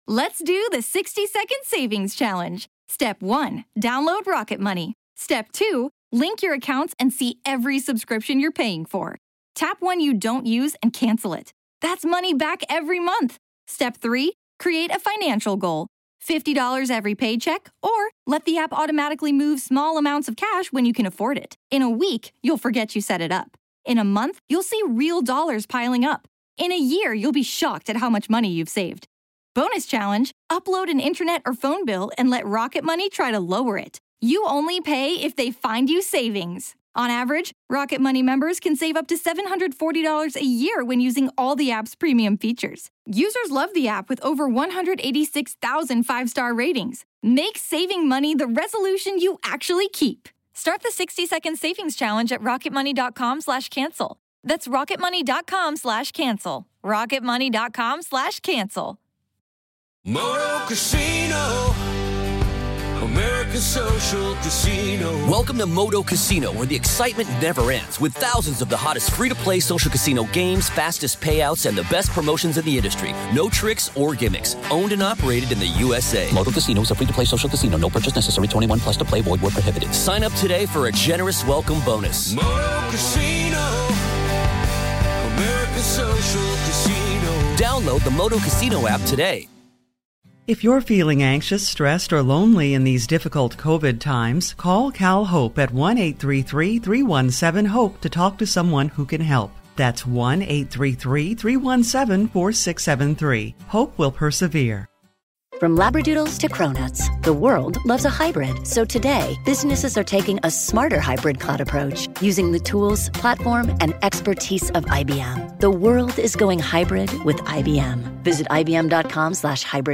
LIVE FROM THE MLB WINTER MEETINGS